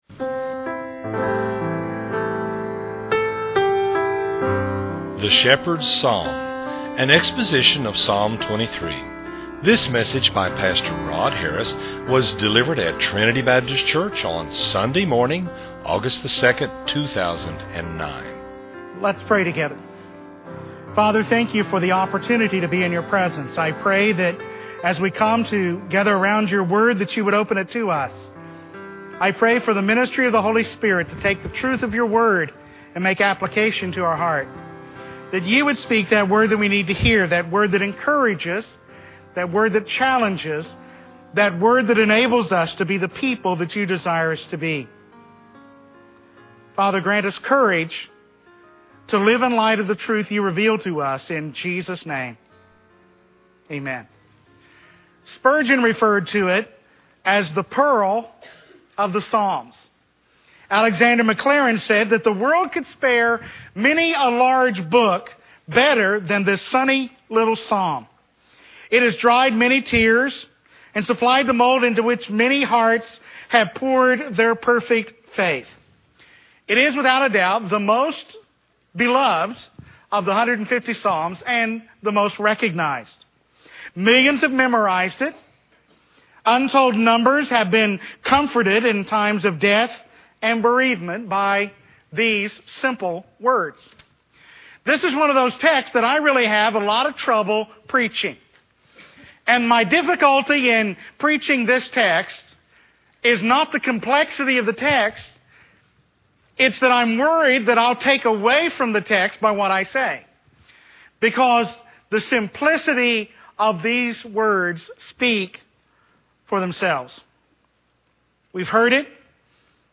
delivered at Trinity Baptist Church on Sunday morning